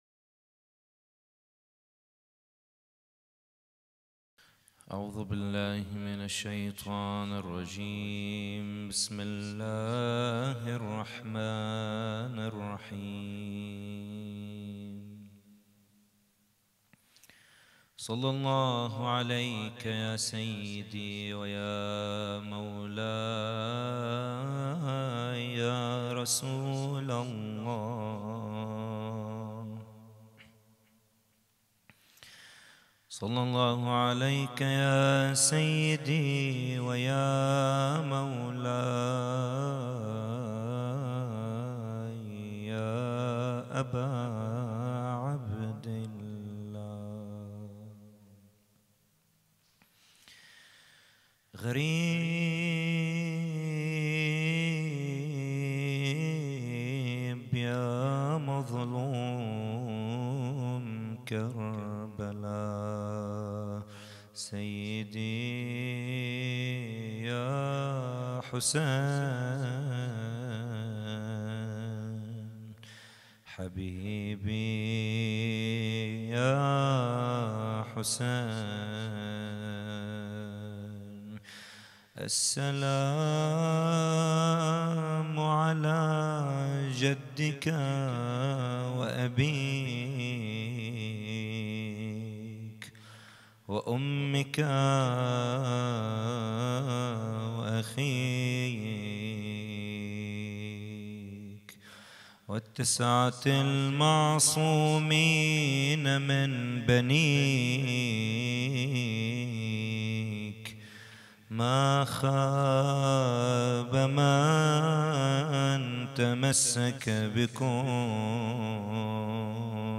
محاضرة
احياء الليلة الثانية عشر من محرم 1442 ه.ق - هیأت رایة العباس لبنان